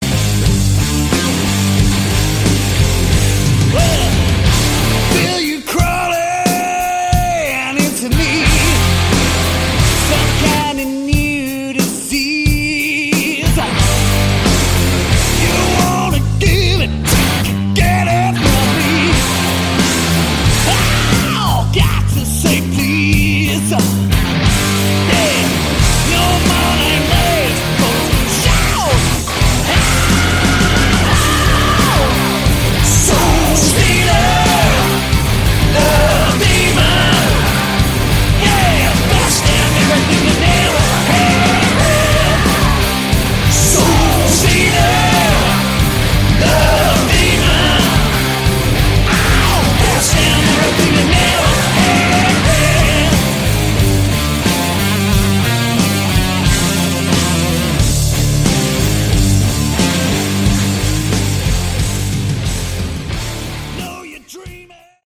Category: Hard Rock
vocals, guitars
bass, backing vocals
drums, percussion